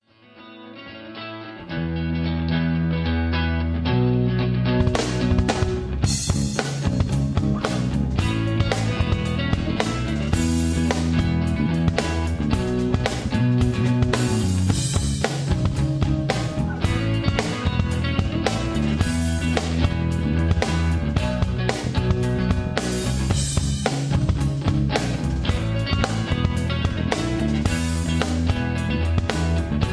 karaoke
backingtracks